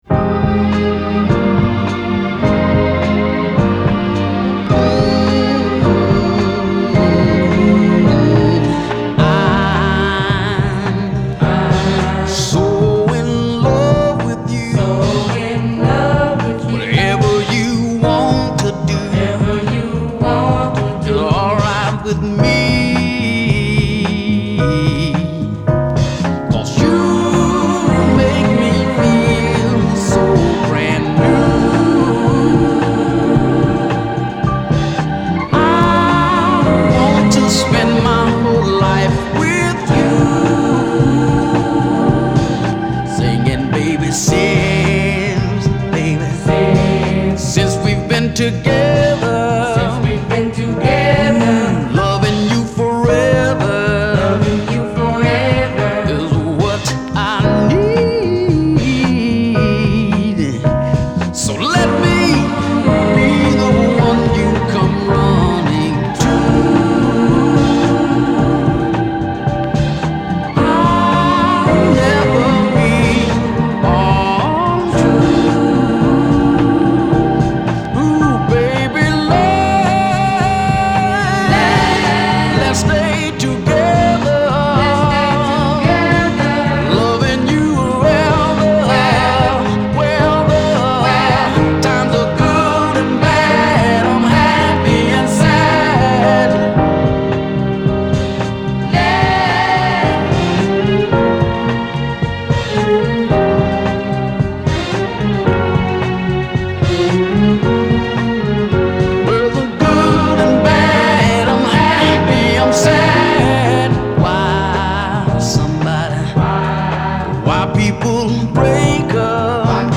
Posted by on December 2, 2014 in Soul/R&B and tagged , , .